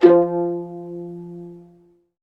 VIOLINP GN-R.wav